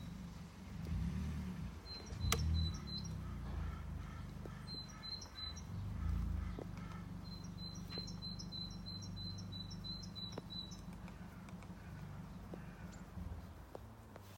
Lielā zīlīte, Parus major
Administratīvā teritorijaValkas novads
StatussDzied ligzdošanai piemērotā biotopā (D)